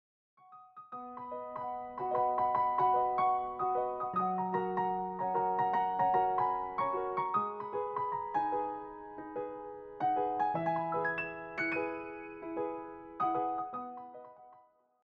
clear and expressive piano arrangements